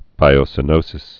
(bīō-sĭ-nōsĭs)